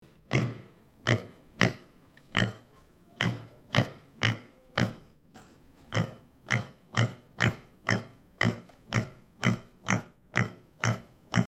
Prasátko se rádo válí v blátě a u toho chrochtá: „Chro chro!“
prase.mp3